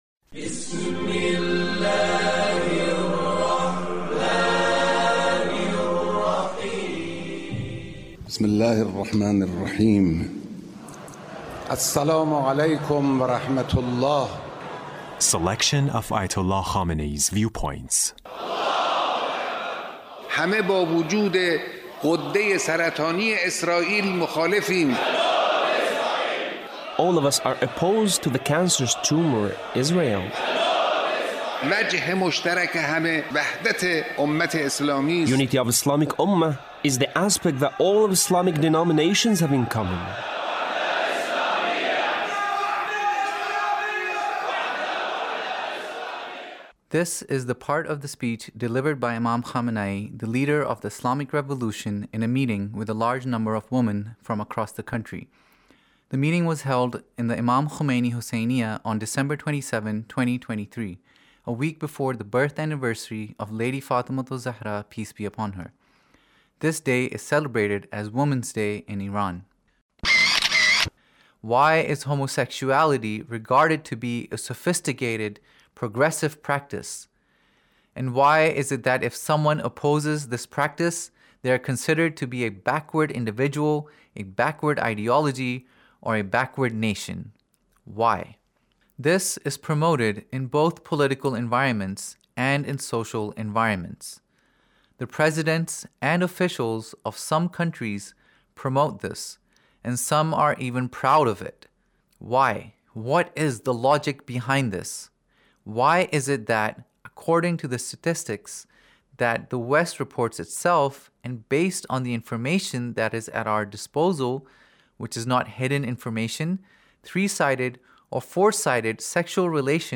Leader's Speech (1882)
Leader's Speech about , in a meeting with Ladies